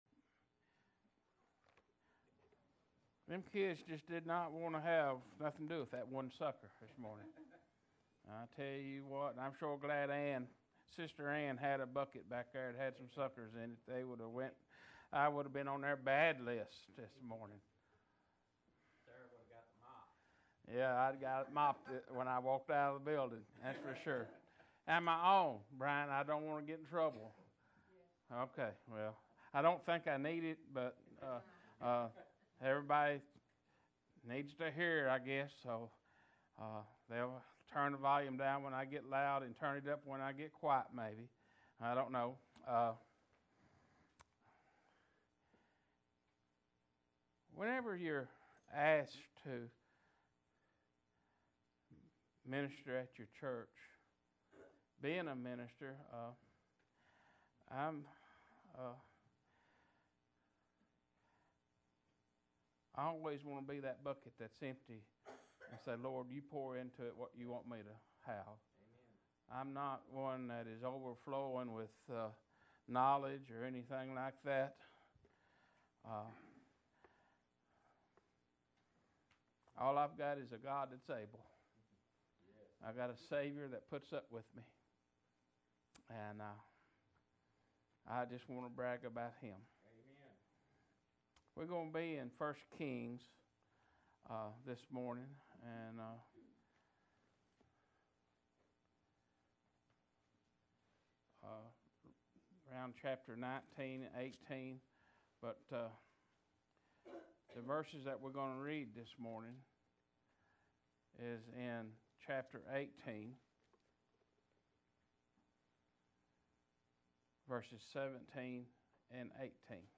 17-18 Service Type: Morning Worship « The Forgiveness Of Sins Are We Mature Christians Or Babes On Milk?